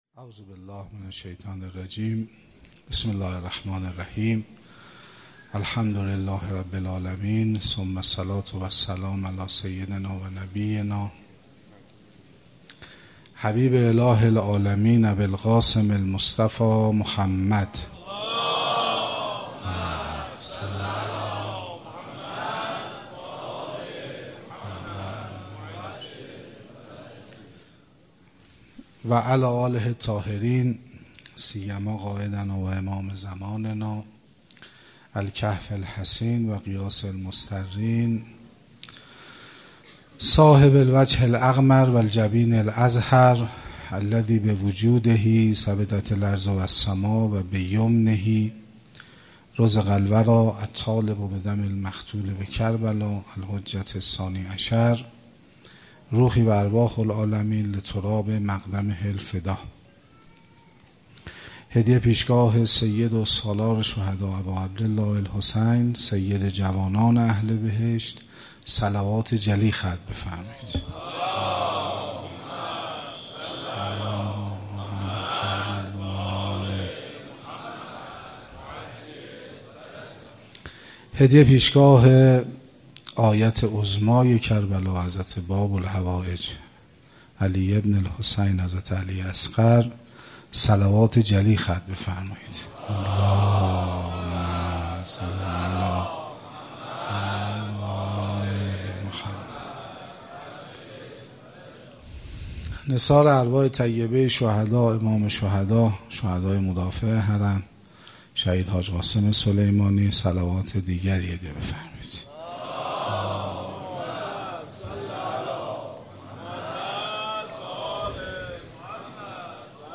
هیات ثارالله زنجان محرم ۱۴۰۲